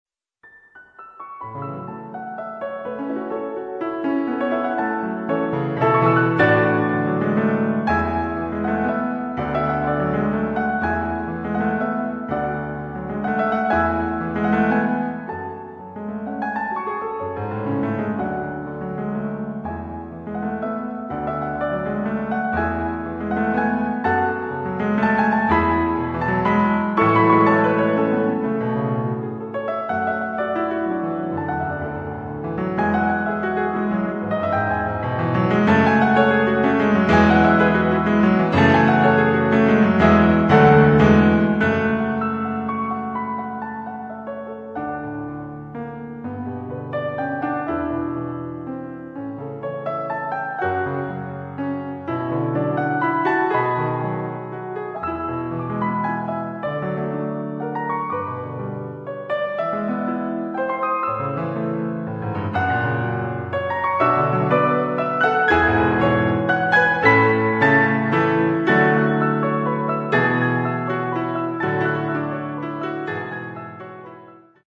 PIANO DUO COLLECTION